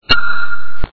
Military Sound Effects
The sound bytes heard on this page have quirks and are low quality.
SONAR # 2 ( A SINGLE PULSE ) 0.78